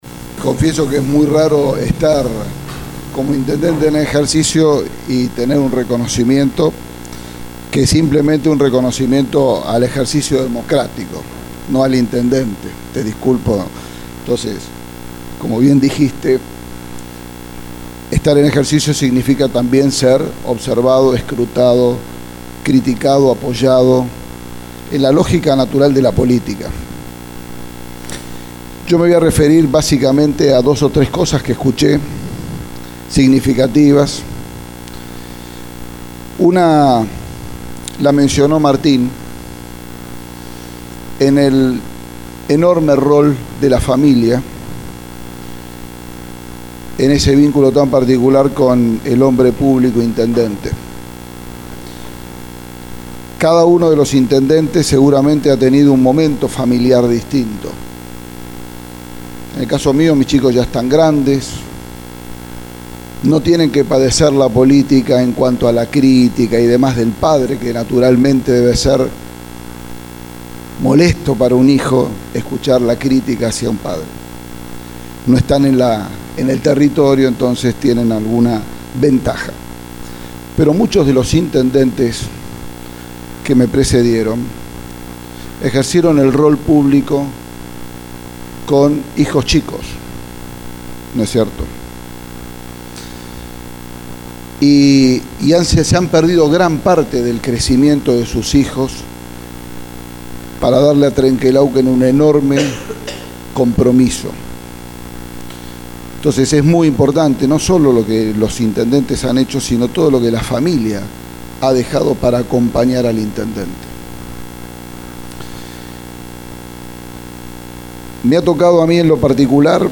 Quien cerró el Acto por el día de la Restauración de la Democracia, en el Concejo Deliberante fue el Intendente Miguel Fernández que dijo lo siguiente.
Miguel-Fernandez-acto-dia-democracia.mp3